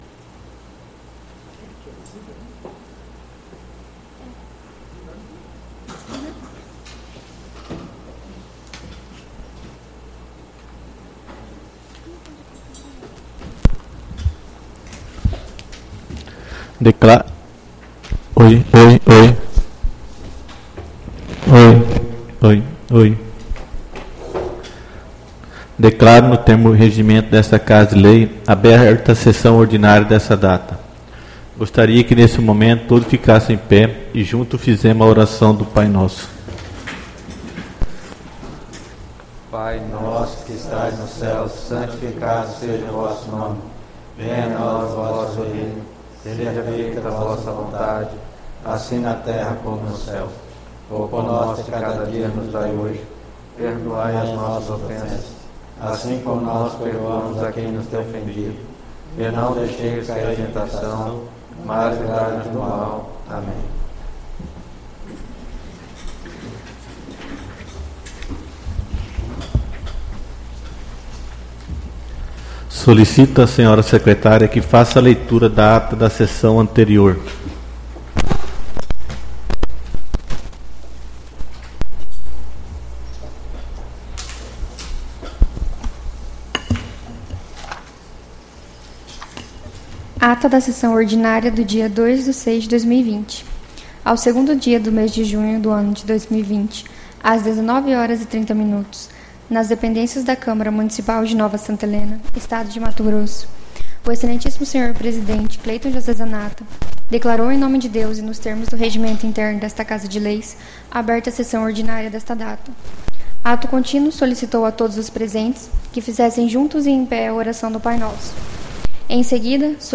SESSÃO ORDINÁRIA DO DIA 09/06/2020